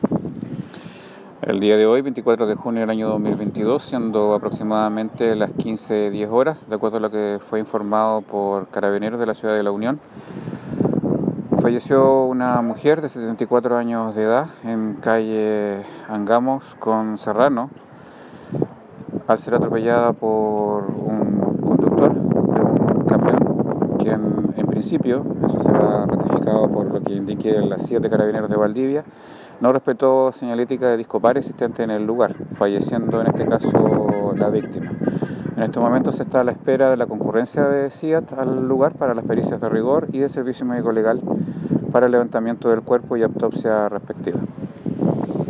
fiscal Raúl Suárez sobre el atropello que causó la muerte de una mujer de 74 años esta tarde en la comuna de La Unión.